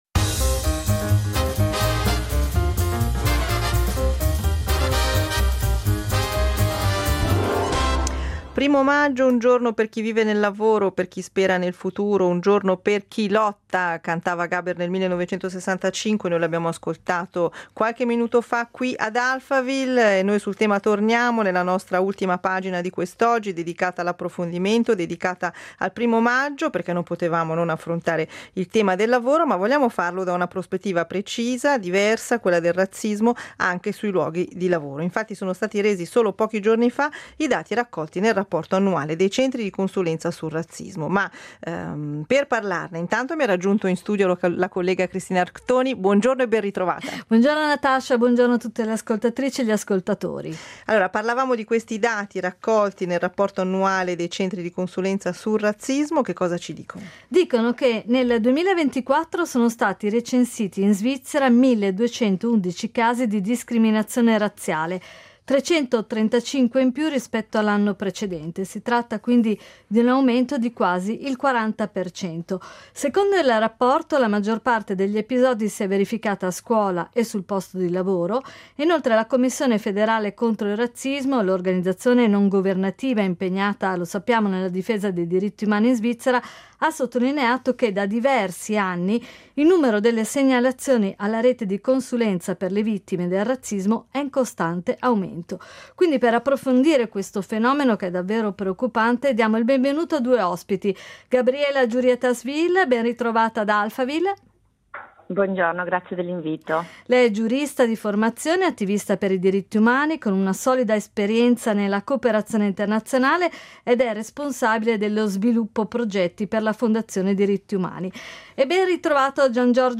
Abbiamo parlato del preoccupante fenomeno con due ospiti: